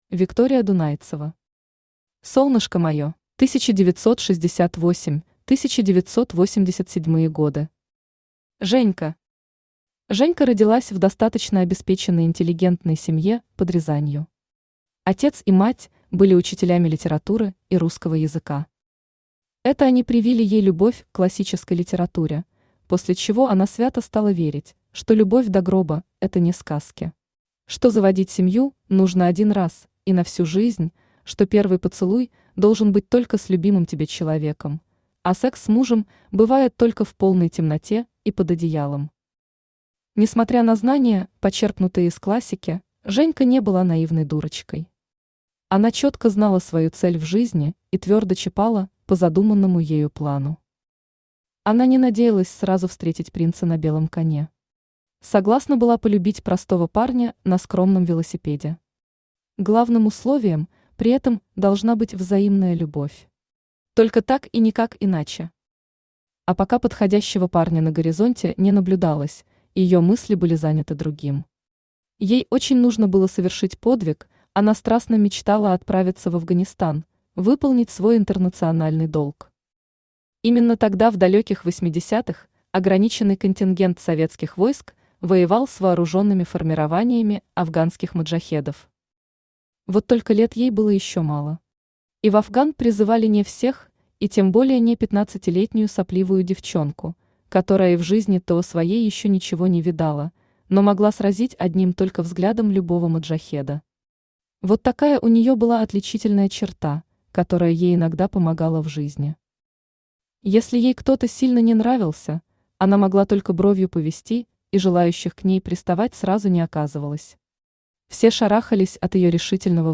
Аудиокнига Солнышко мое | Библиотека аудиокниг
Aудиокнига Солнышко мое Автор Виктория Анатольевна Дунайцева Читает аудиокнигу Авточтец ЛитРес.